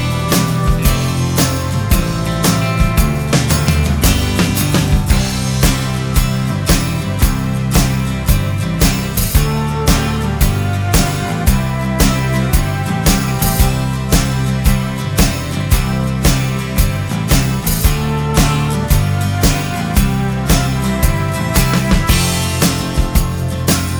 For Duet Pop (1980s) 3:54 Buy £1.50